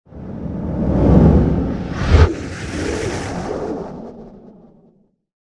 mixed-ghost-voices